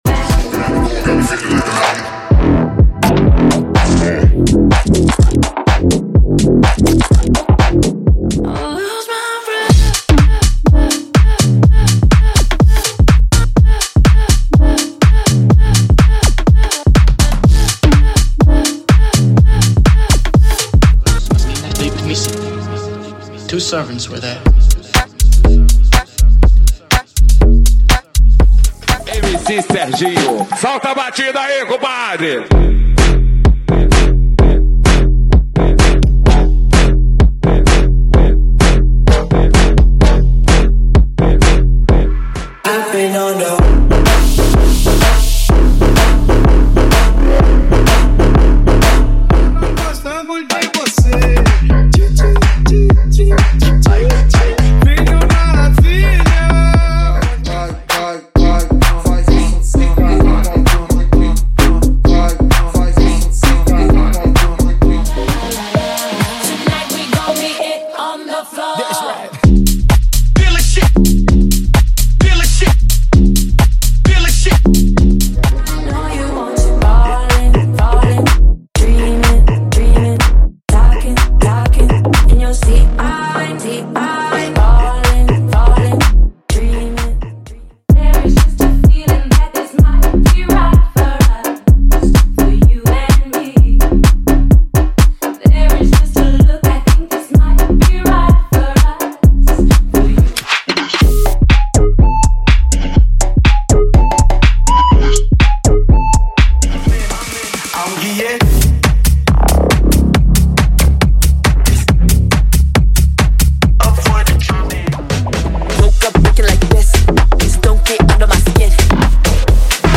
DESANDE HOUSE = 130 Músicas
Sem Vinhetas